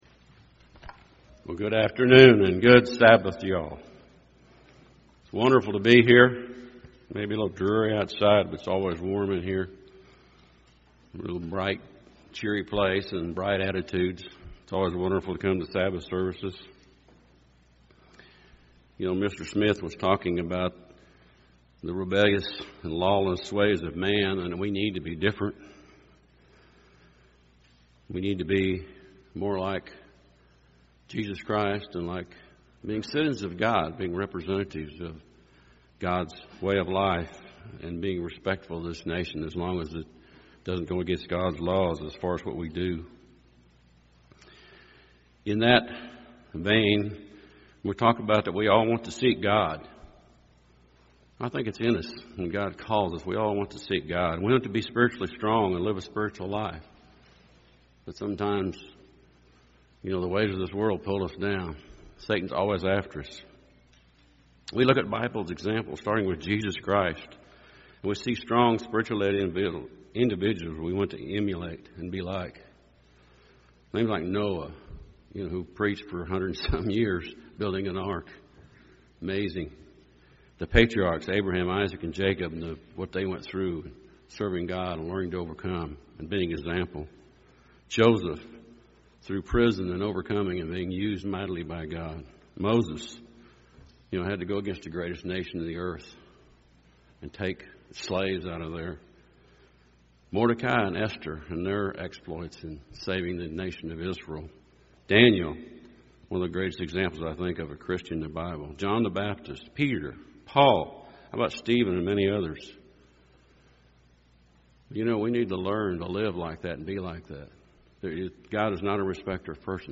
Fasting is the way of God, the way to God UCG Sermon Studying the bible?